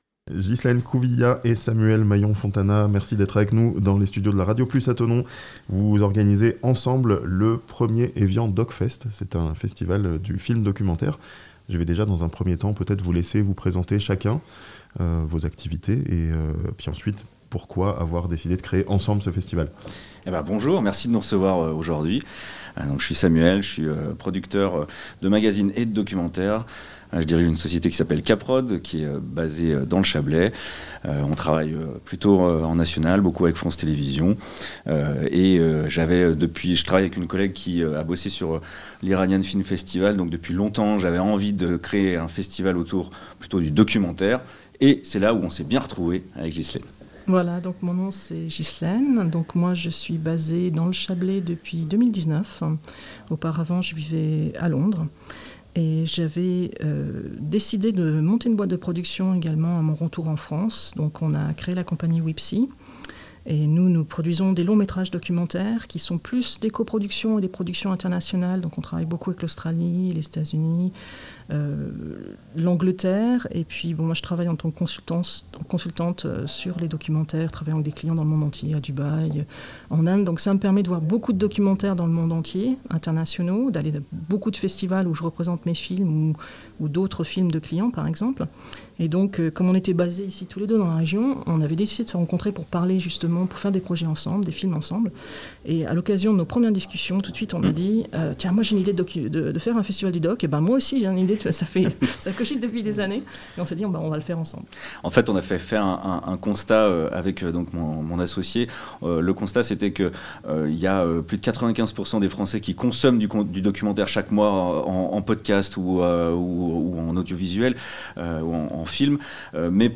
eviandocfest-72366.mp3